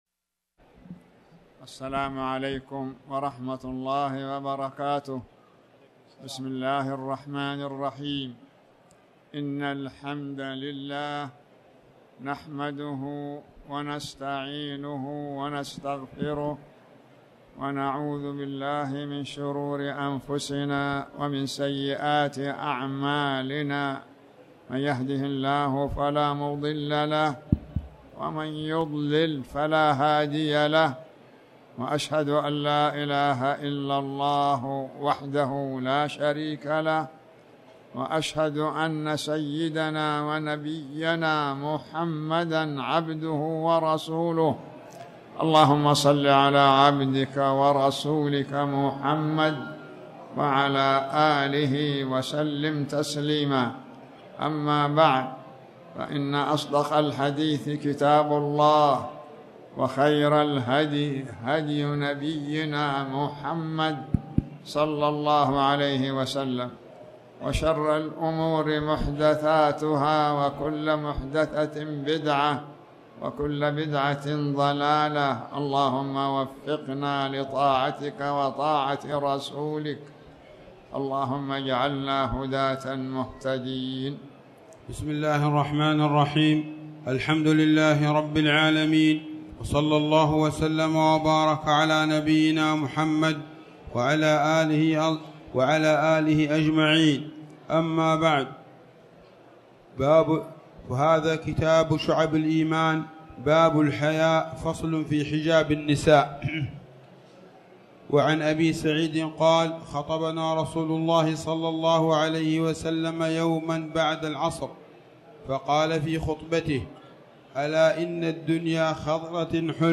تاريخ النشر ٢٤ رجب ١٤٣٩ هـ المكان: المسجد الحرام الشيخ